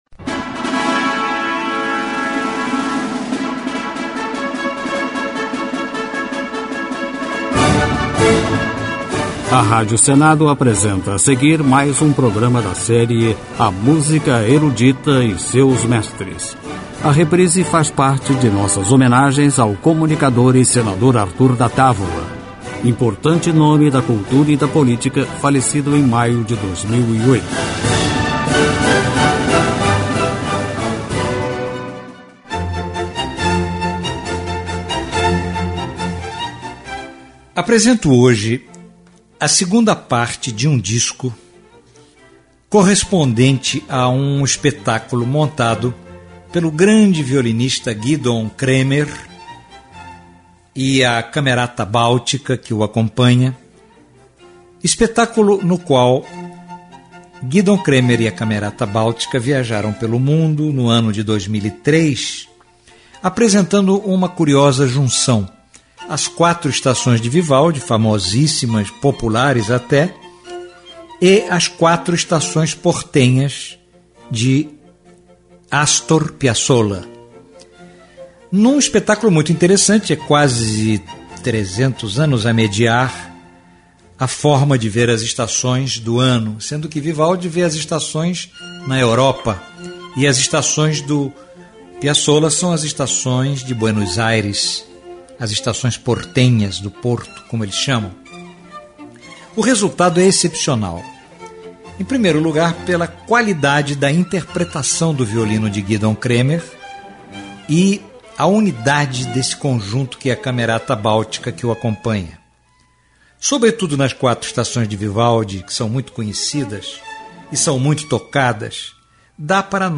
Concerto em Fá Maior
Concerto em Fá Menor
Música Erudita
Período Barroco